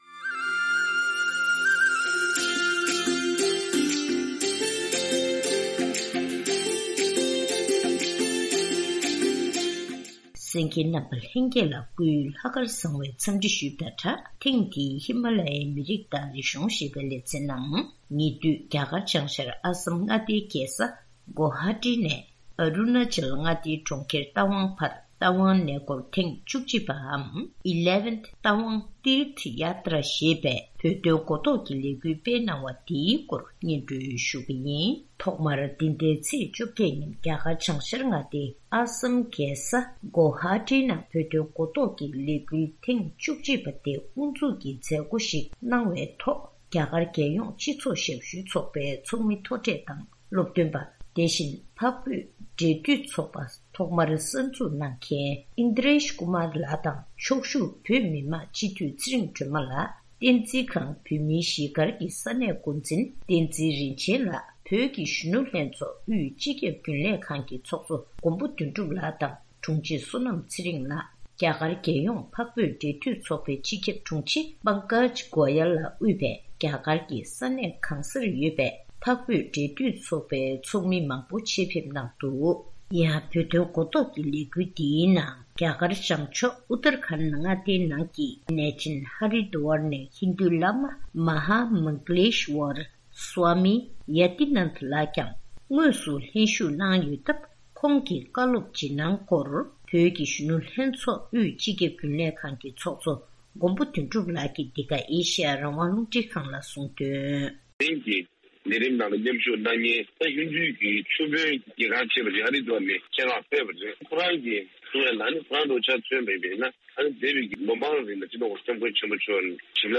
གནས་འདྲི་ཞུས་ནས་གནས་ཚུལ་ཕྱོགས་བསྒྲིགས་ཞུས་པ་ཞིག་གསན་རོགས་གནང་།།